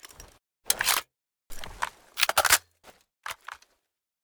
Divergent / mods / Spas-12 Reanimation / gamedata / sounds / weapons / spas / reload.ogg
reload.ogg